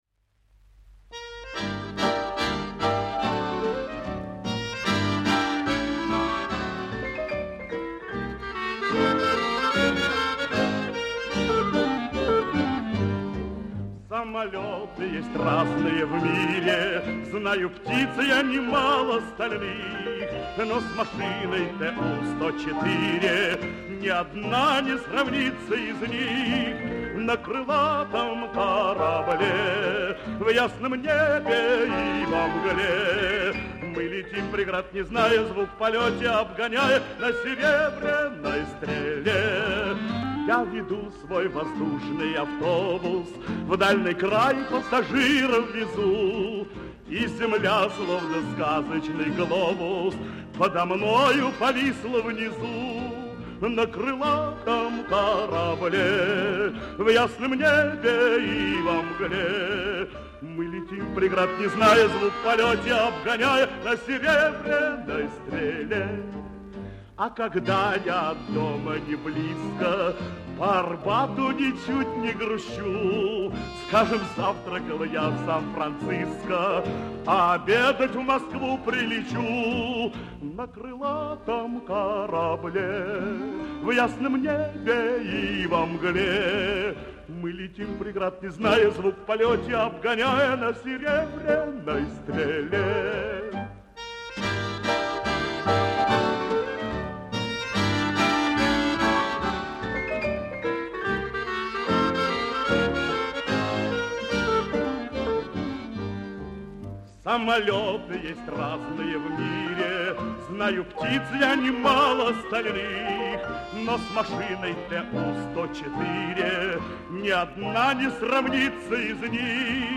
Инстр. квинтет